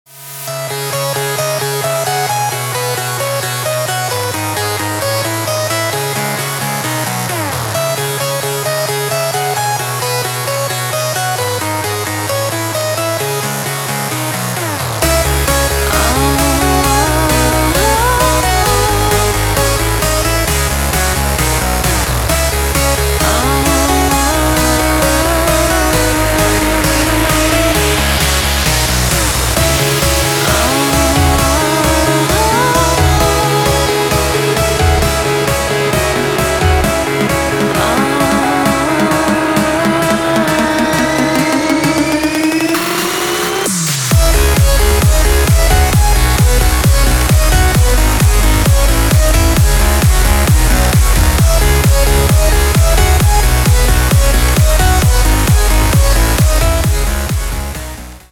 • Качество: 256, Stereo
громкие
женский голос
EDM
Trance